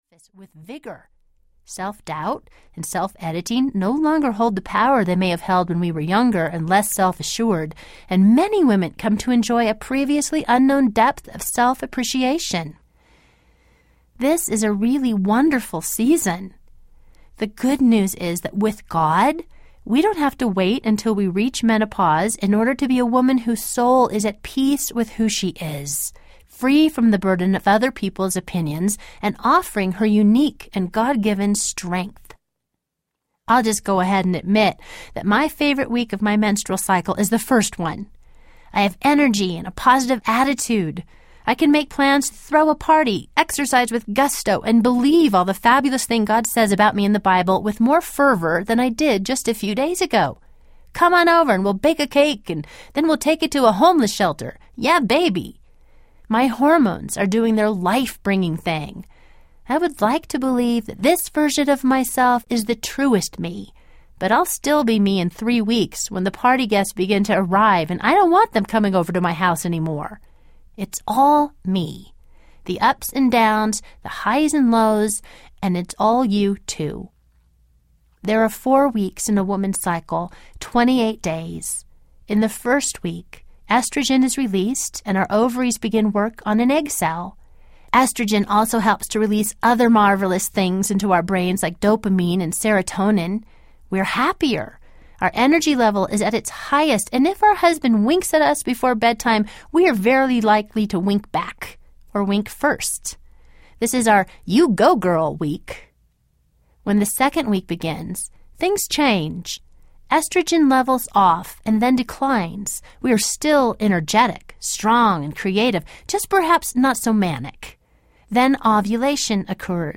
Becoming Myself Audiobook
8.3 Hrs. – Unabridged